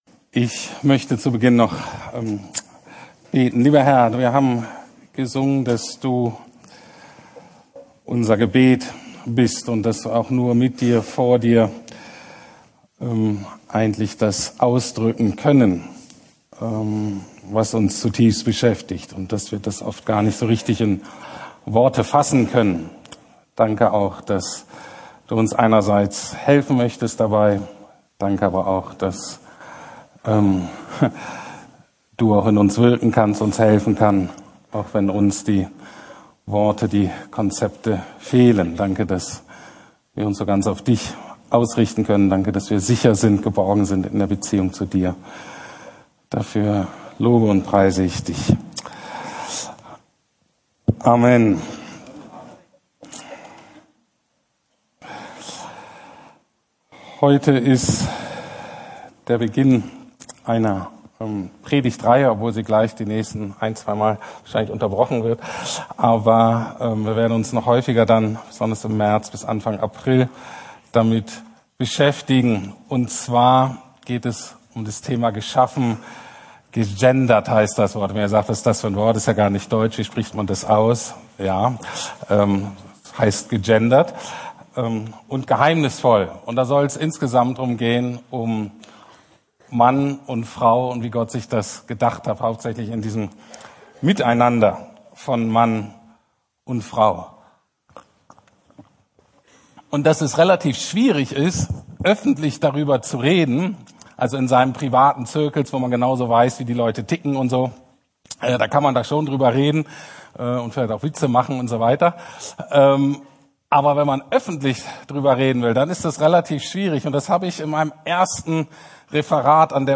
Mann und Frau im Bilde Gottes ~ Predigten der LUKAS GEMEINDE Podcast